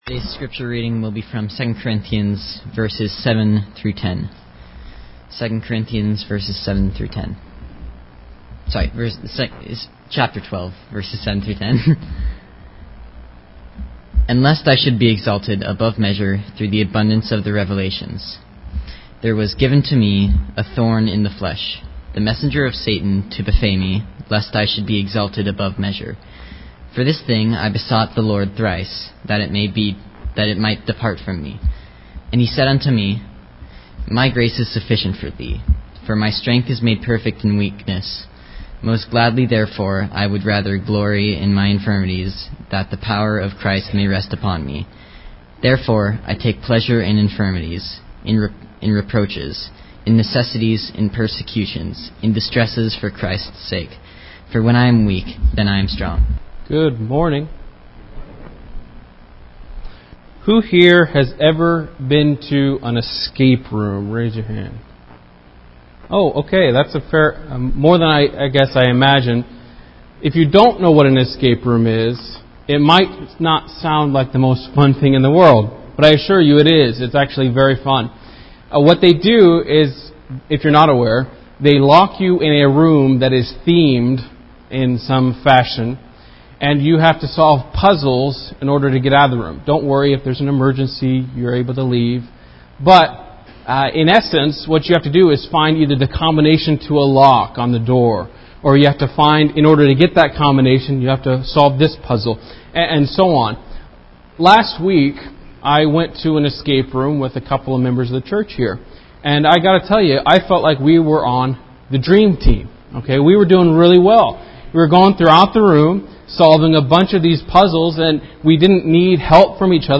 The scripture reading for this lesson was Ezekial 11:12